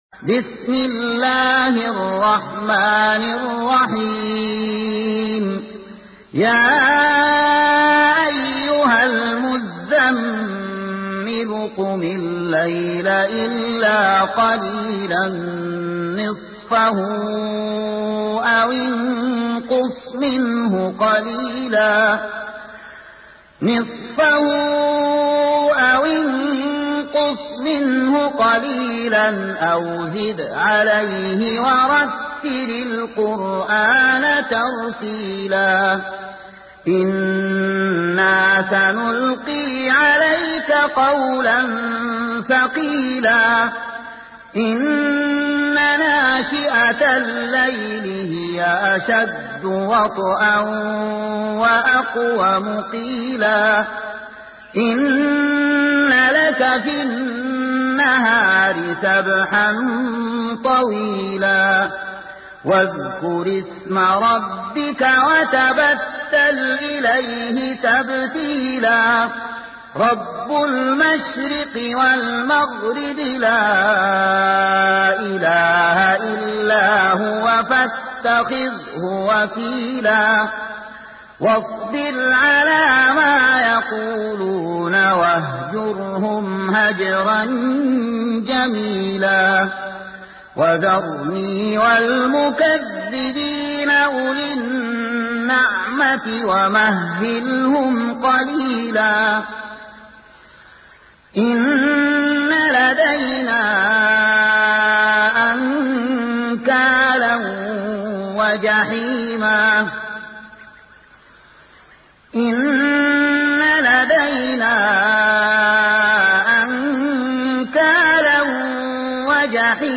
سورة المزمل مكية عدد الآيات:20 مكتوبة بخط عثماني كبير واضح من المصحف الشريف مع التفسير والتلاوة بصوت مشاهير القراء من موقع القرآن الكريم إسلام أون لاين